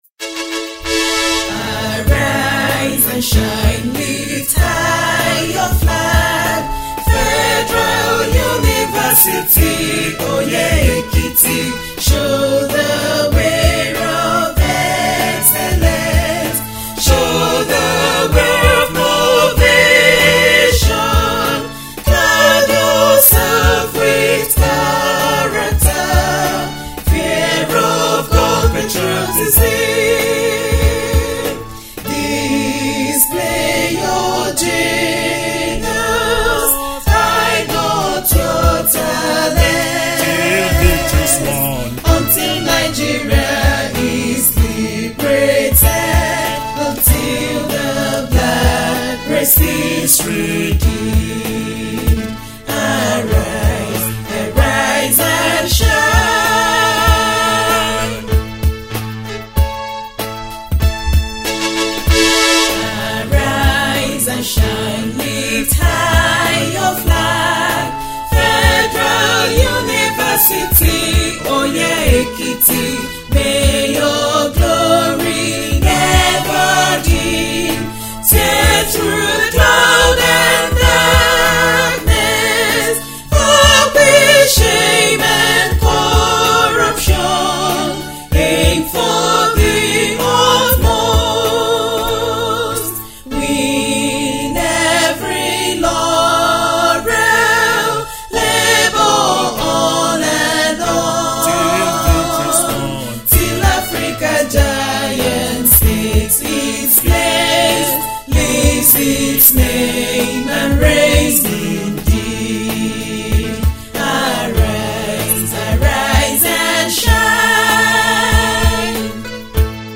official school anthem